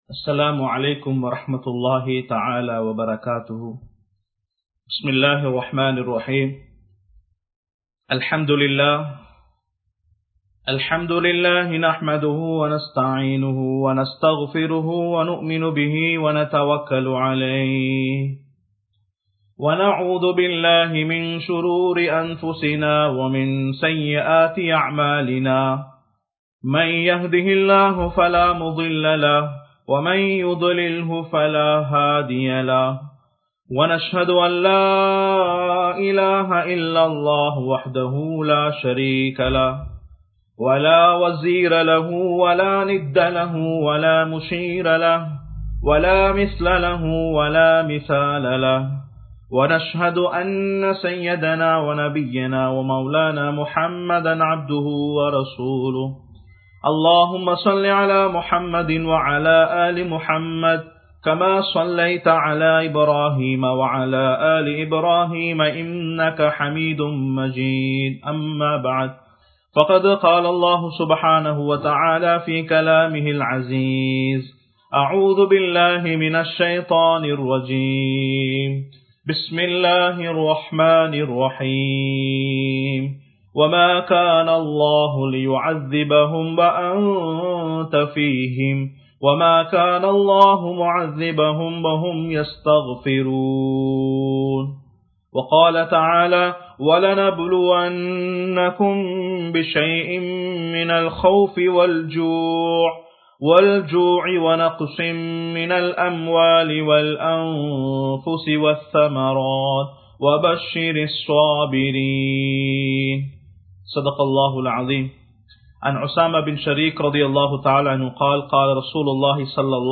America vum Allahvin Vallamaium (அமெரிக்காவும் அல்லாஹ்வின் வல்லமையும்) | Audio Bayans | All Ceylon Muslim Youth Community | Addalaichenai
Live Stream